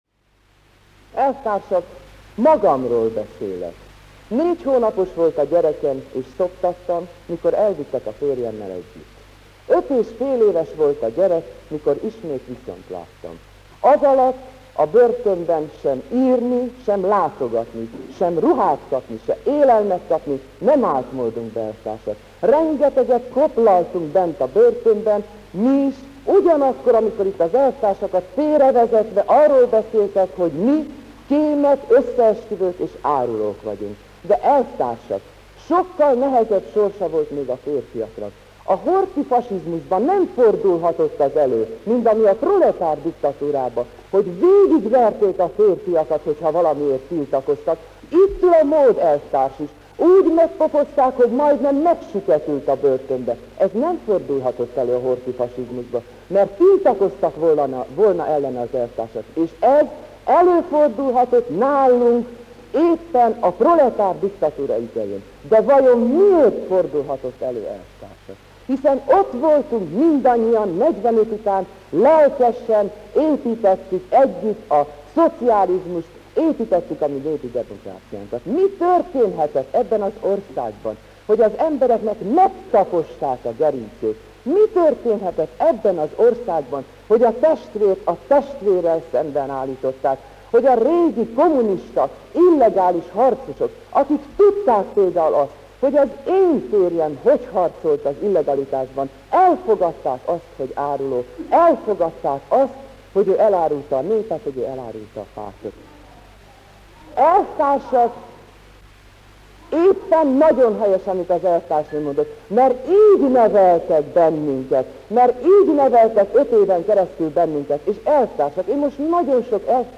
A Rajk László és társai elleni fő per egyetlen magyar életben maradt vádlottja: Justus Pál elvtárs ügyében a Legfőbb Bíróság néhány nappal ezelőtt mondta ki az ítéletet, amely szerint bűncselekmény hiányában felmenti őt. Megkérdeztük Justus Pál elvtársat, hogy van-e valami személyes mondanivalója a hét évvel ezelőtti per kapcsán.